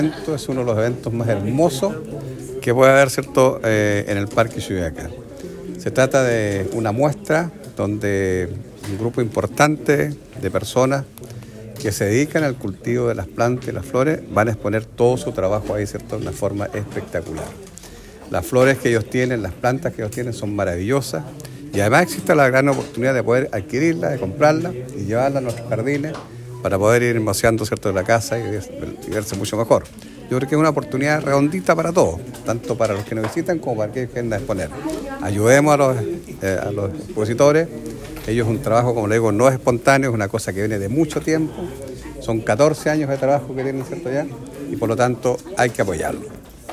El Alcalde de Osorno, Jaime Bertin, destacó que esta sea la primera actividad del verano en la comuna, pues de esta forma se entrega un espacio para que quienes se dedican a la producción de plantas puedan exhibirlas y venderlas a la comunidad.